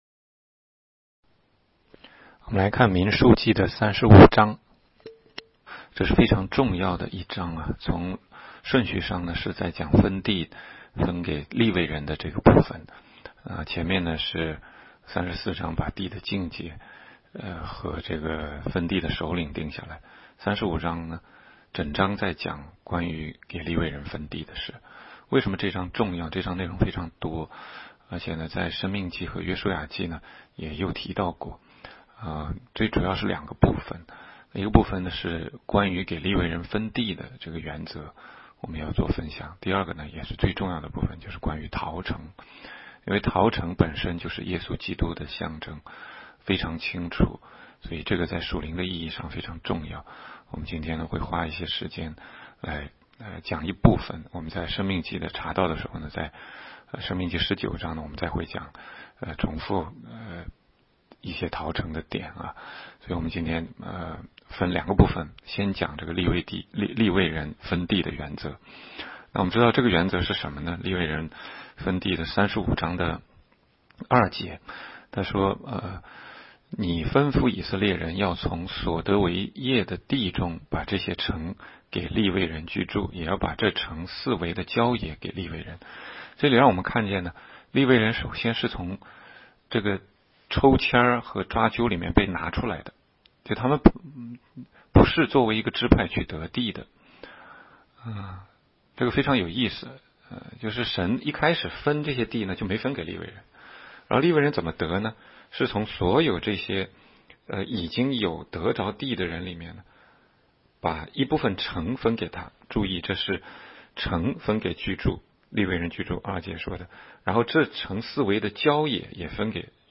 16街讲道录音 - 每日读经-《民数记》35章